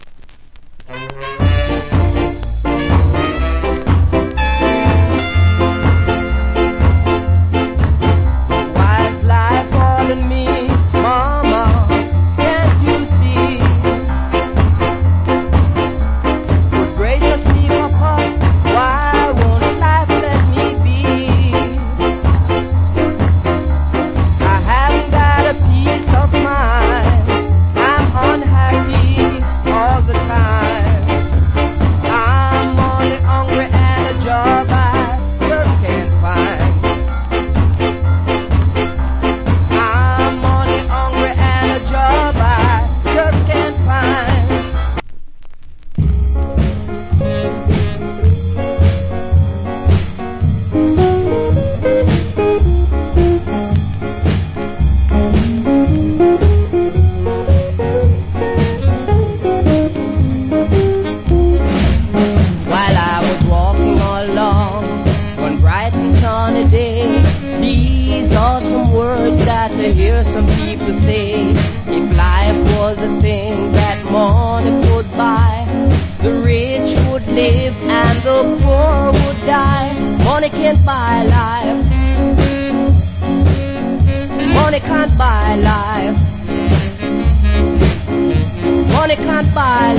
コメント Wicked Ska Vocal. / Old Hits. Ska Vocal.